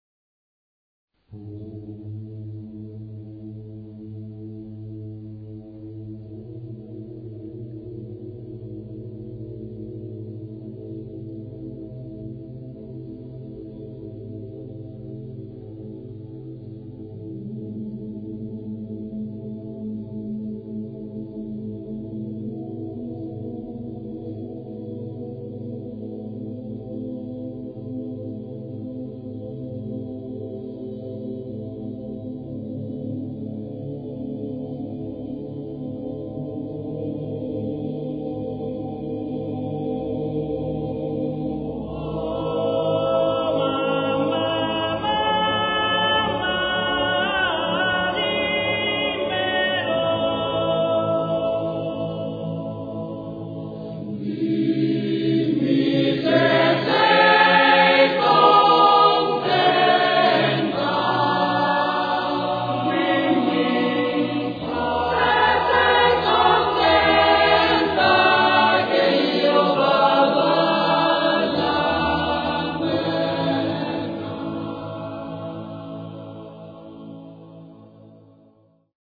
[ voci virili ]
Ancora da Gaggio Montano viene questa bella melodia che riveste un tema noto in numerose regioni italiane.